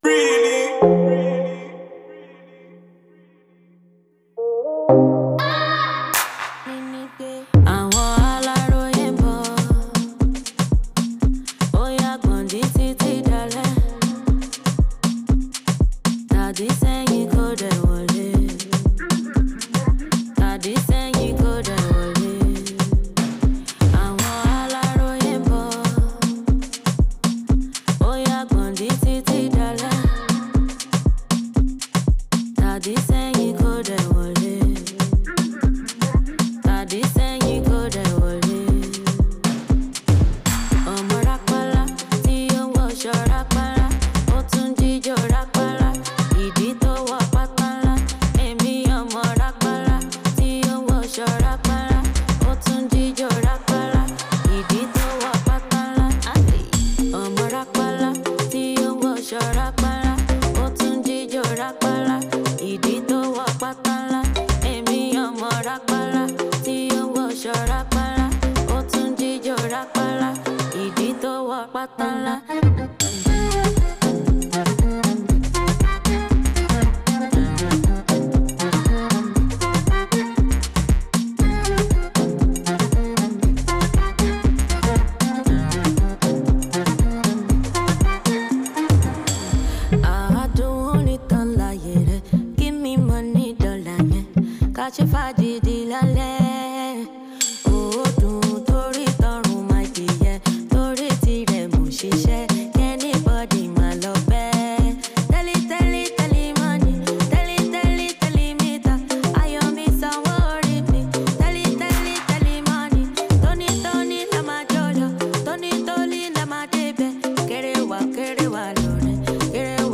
Afro-house